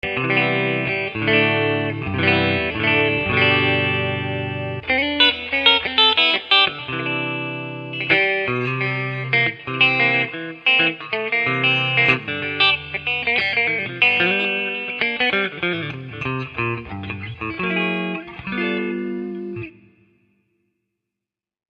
This clip was recorded clean on my computer using a Sure SM57 microphone. I played it through my home made 15 watt tube amp with no effects except a bit of reverb. The select switch on the guitar was in the middle using both of the humbucking pickups.
riff.mp3